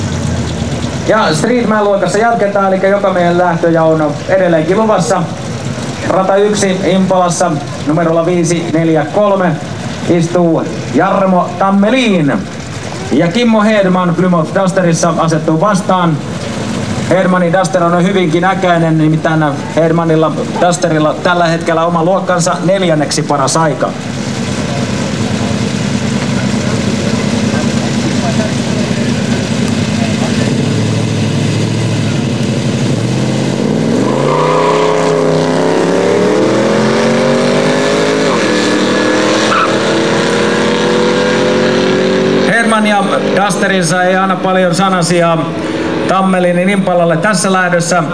Listen me racing 1/4 mile [1037k-16bit] ..or if you have slow modem try this: [283k-8bit] Line-up Starting burn out Tires warmed Staging There i go -67 camaro and me Mopar leaves faster !